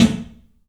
PABSNARE100L.wav